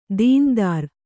♪ dīn dār